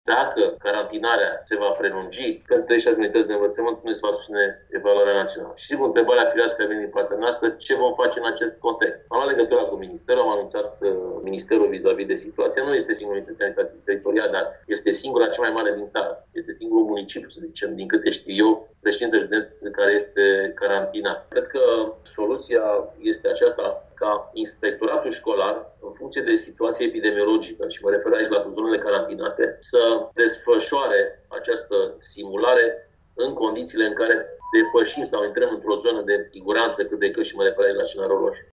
Șeful Inspectoratului Școlar Timiș, Marin Popescu, spune că deocamdată nu se știe câți elevi din județ vor participa la aceste testări.